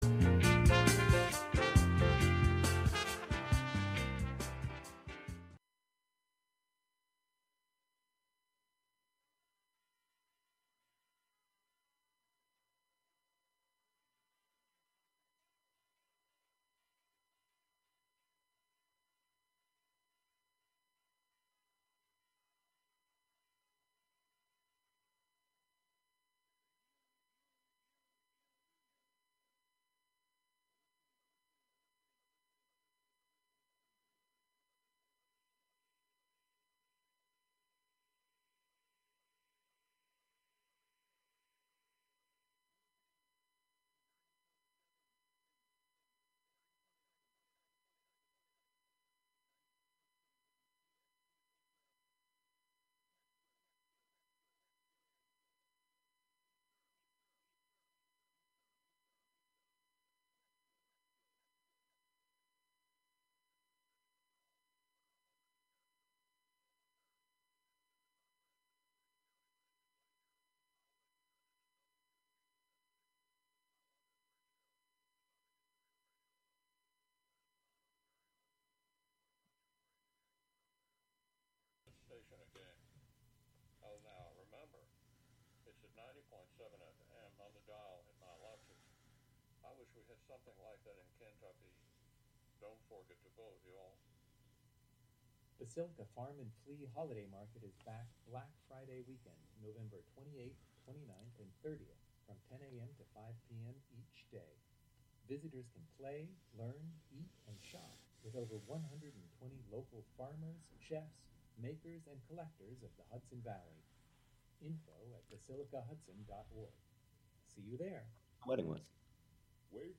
These features offer listeners real, raw, and authentic conversations.